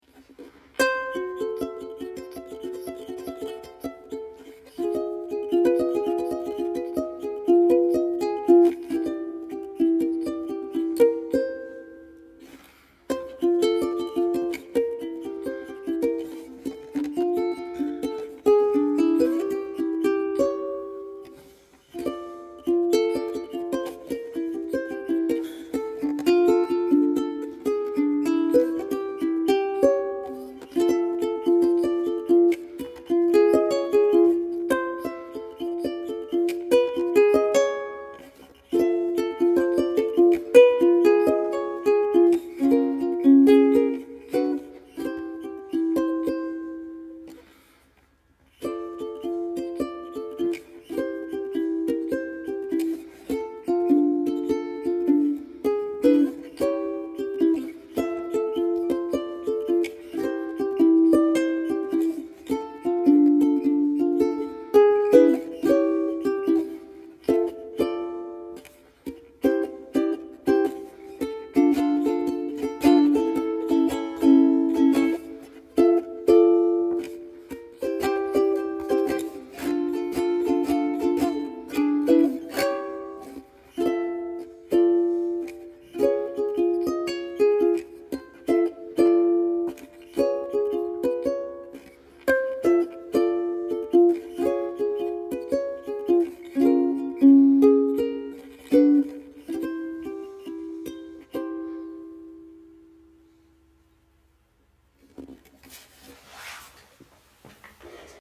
Os dejo una grabación sencilla que he realizado con el ukelele una de estas noches primaverales. El equipo utilizado ha sido el micro T.Bone SC400 y la tarjeta Focusrite 18i6
ukeNight.mp3